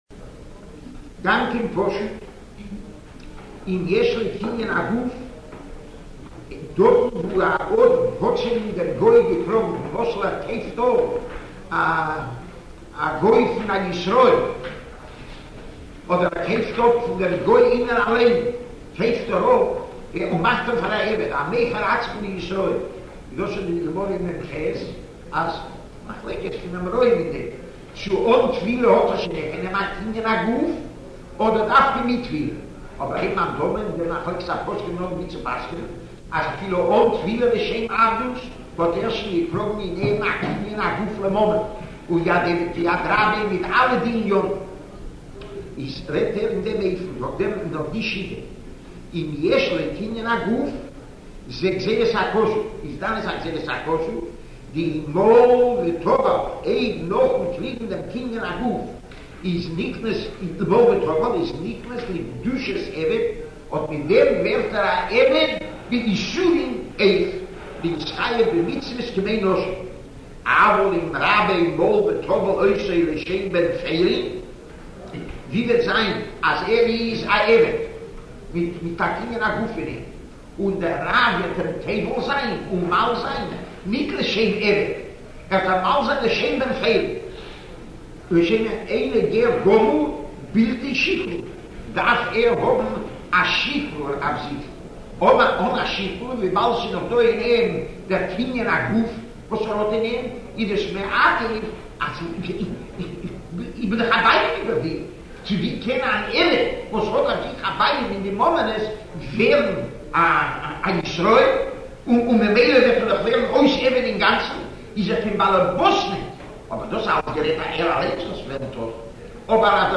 Rav Gifter giving shiur on Minchas Chinuch – Avodim V’Shifchos part II.